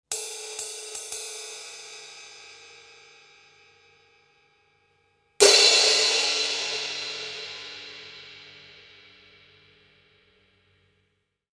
- ein Zildjian EDGE Razor Thin Crash in 17“